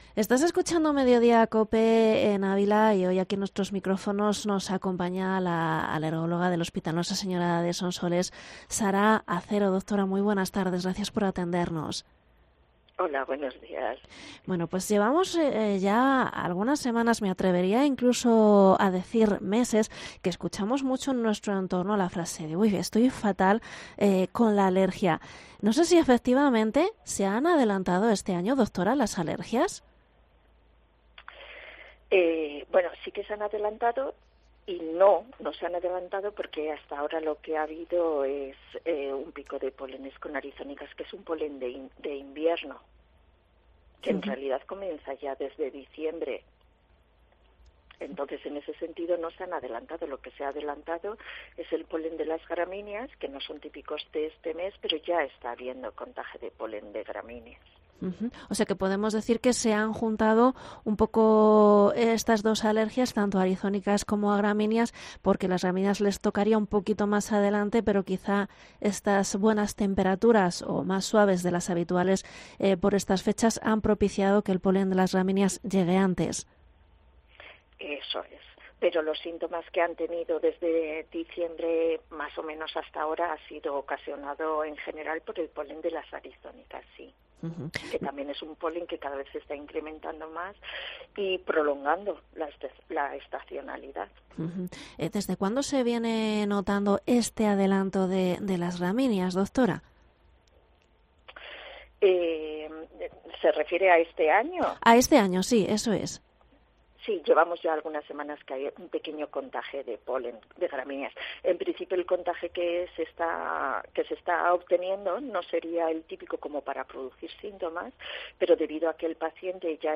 ENTREVISTA a la alergóloga